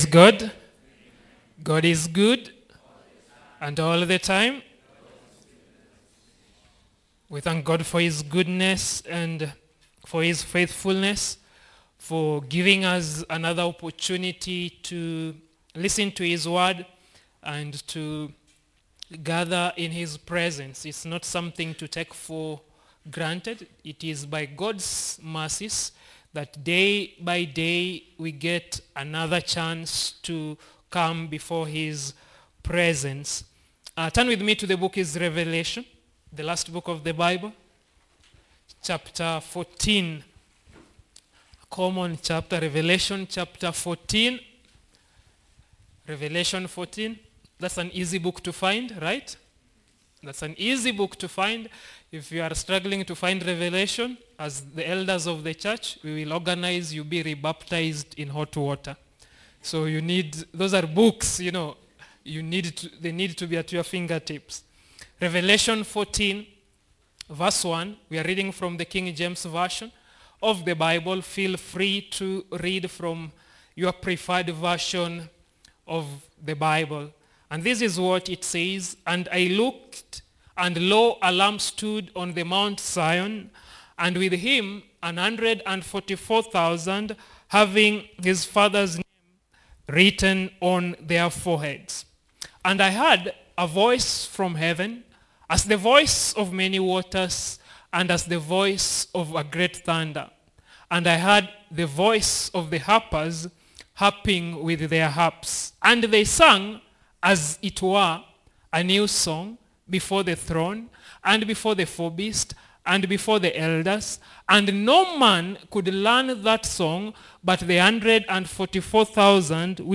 Tuksda Church -Sermons